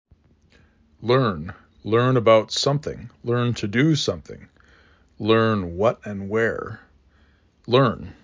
l er n